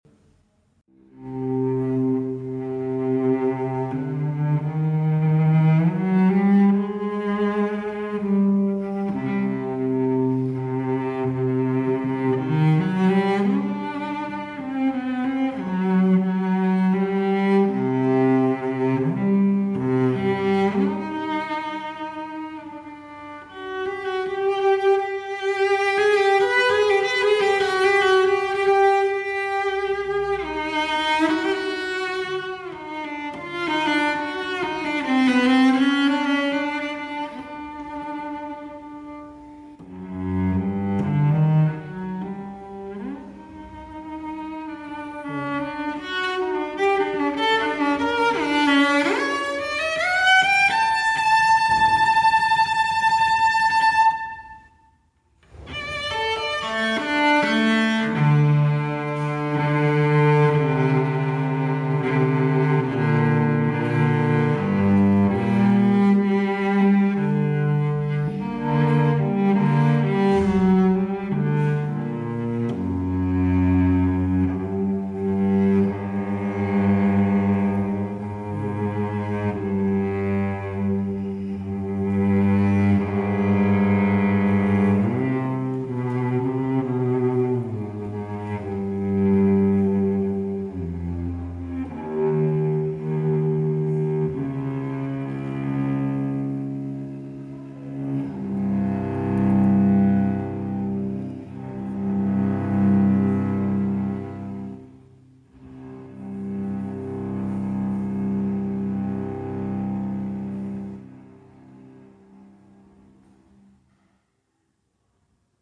für Violoncello solo